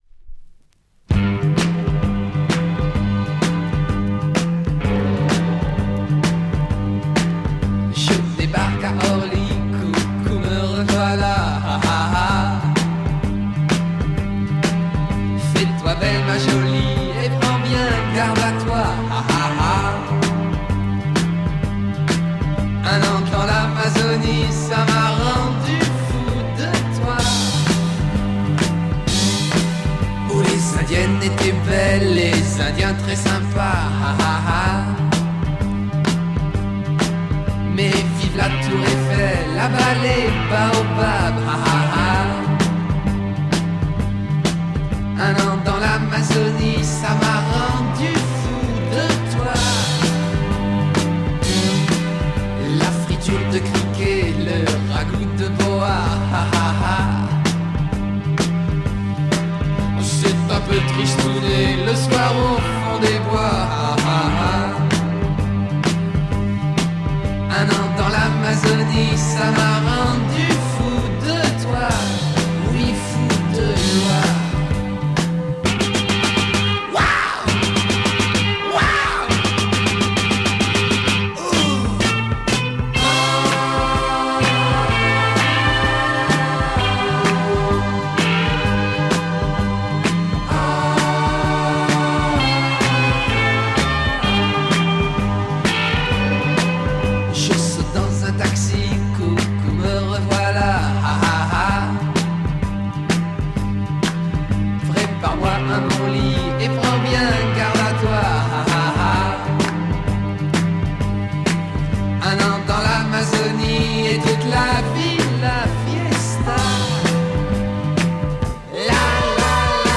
French Glam
Classic French Glam